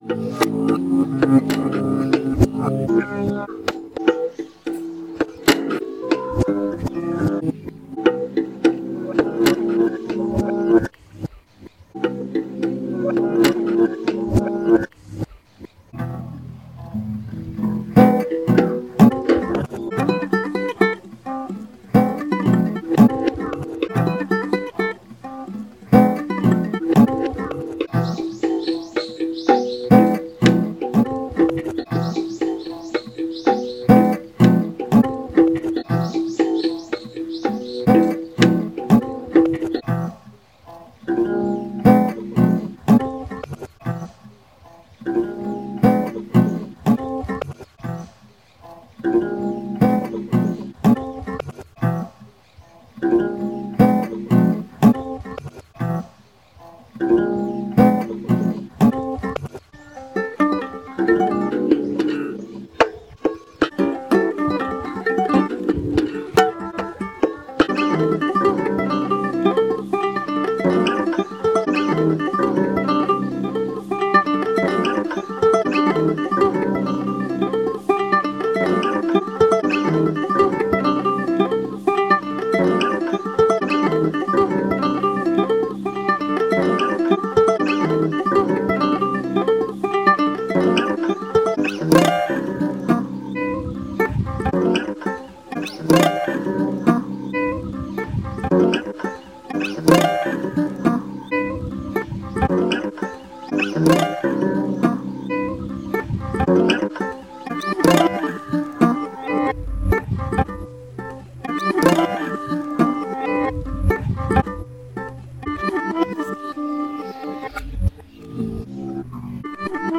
iDoze(asneakpreview): 10min of [SopranoGuitar, UbuntuGuitar, HanamuraYongen, Cajon].padanipa(sa.ri())